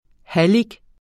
Udtale [ ˈhalig ]